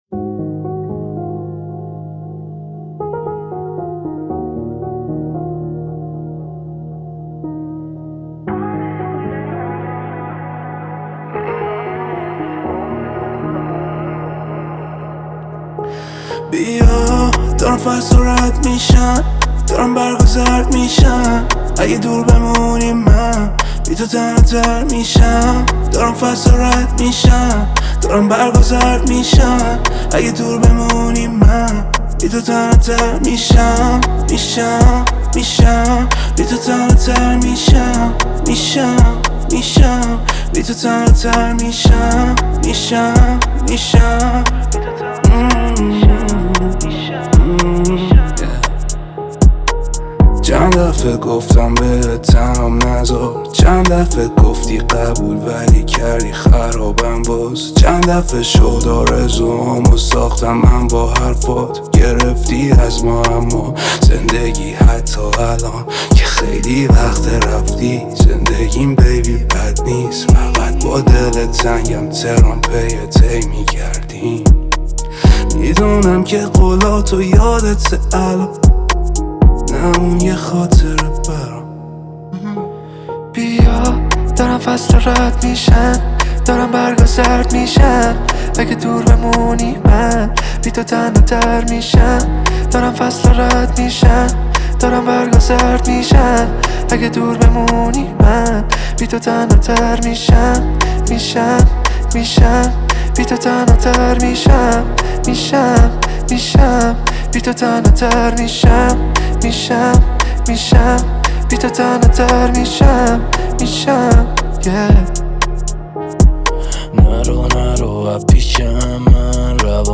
اهنگ جدید رپ